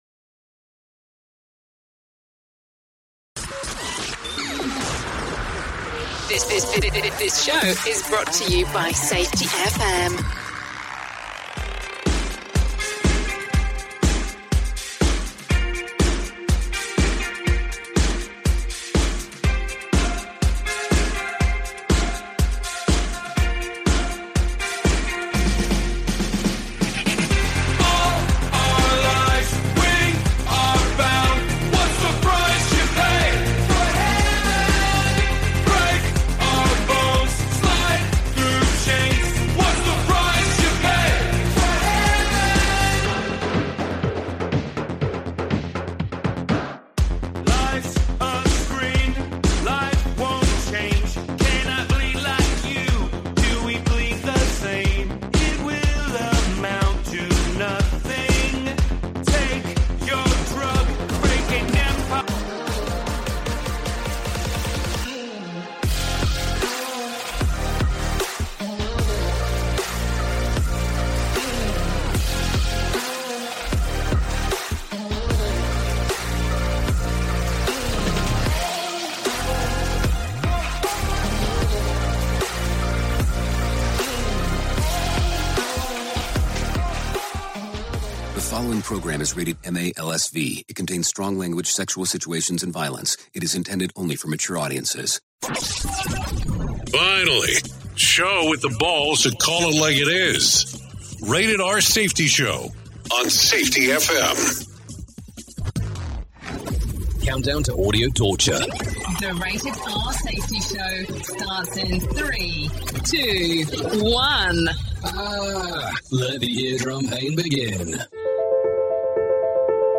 🎤 Expect nothing less than the most insightful and thought-provoking discussions on current events and crucial topics.